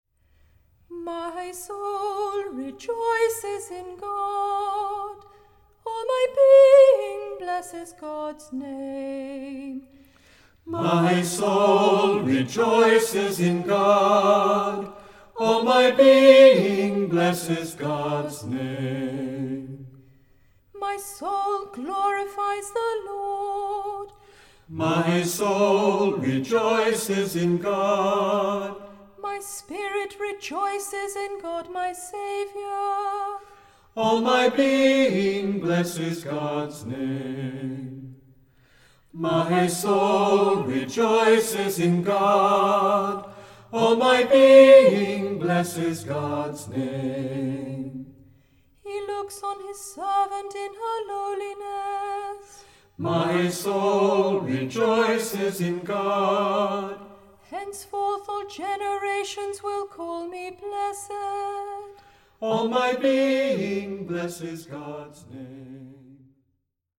A demo recording